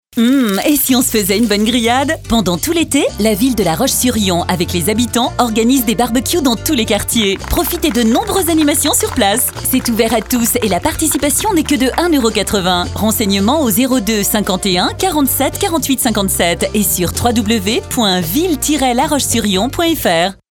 Mairies, Communautés de Communes ou Union des Commerçants, nous avons réalisé pour eux les spots publicitaires qu'ils souhaitaient !